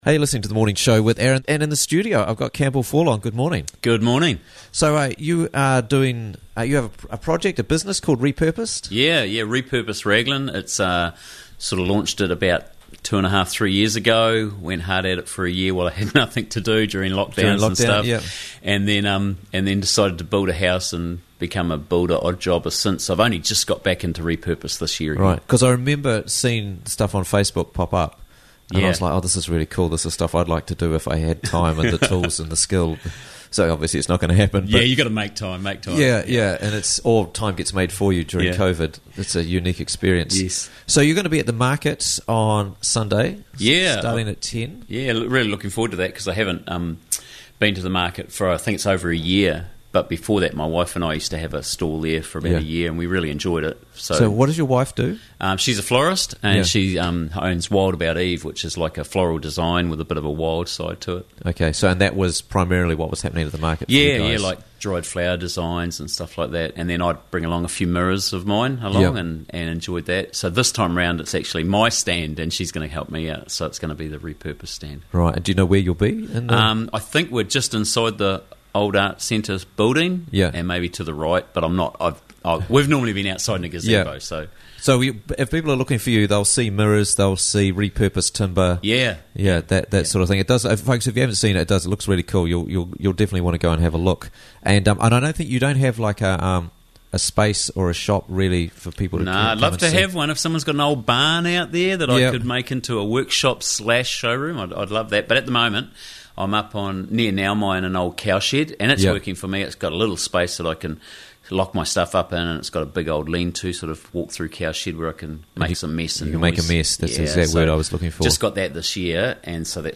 Quickly and easily listen to Interviews from the Raglan Morning Show for free!